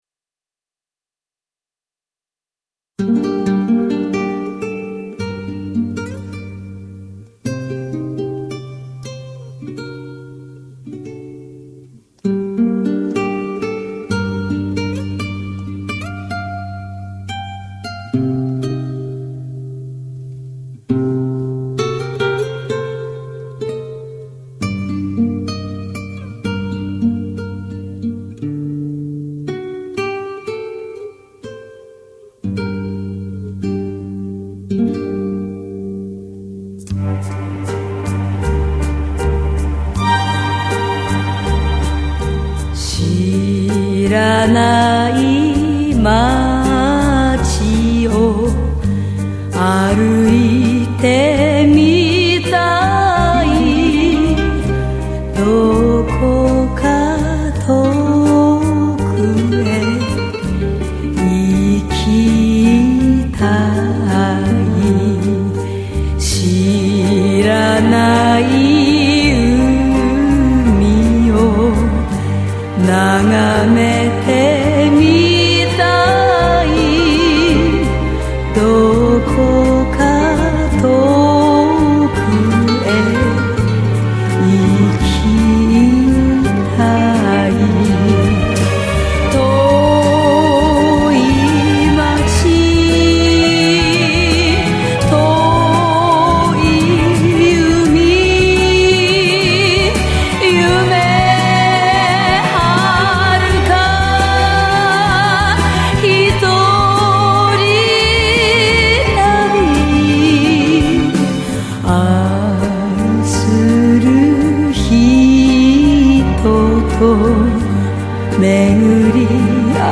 И что за енка её исполняет